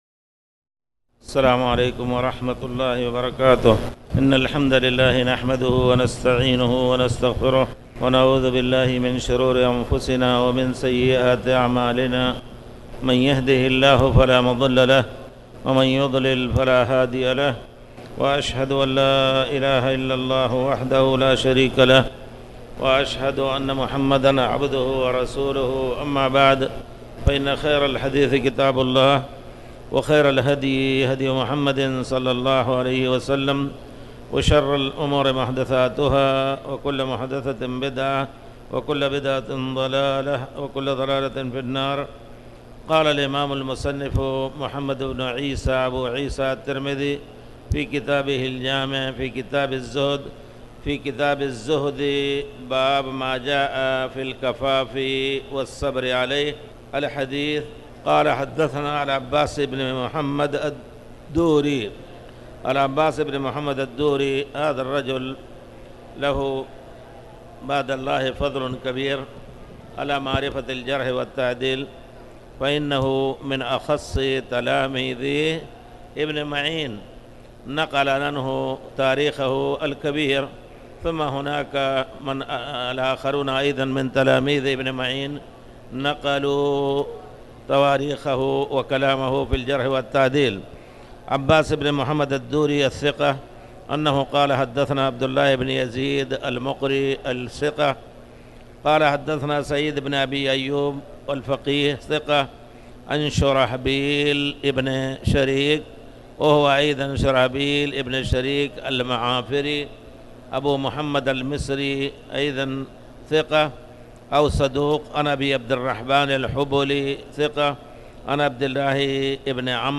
تاريخ النشر ١٤ جمادى الأولى ١٤٣٩ هـ المكان: المسجد الحرام الشيخ